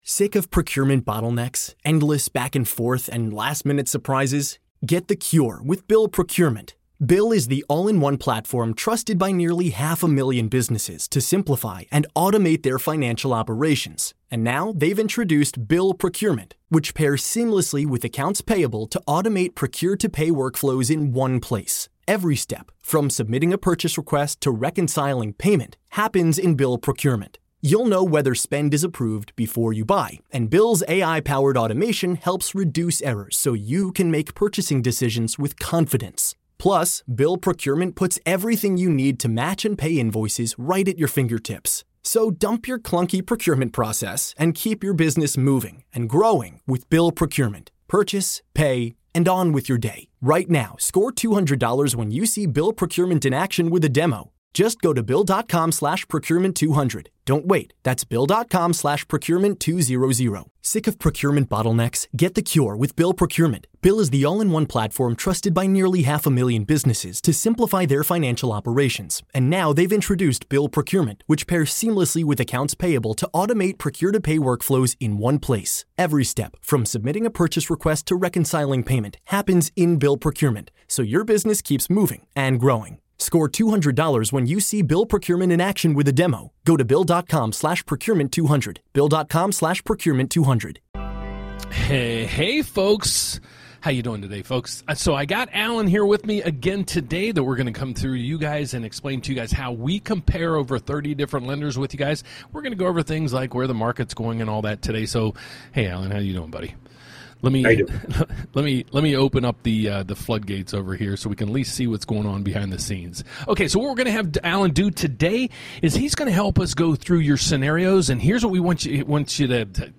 Live Tutorial: Find the Lowest Mortgage Rate Among 30+ Lenders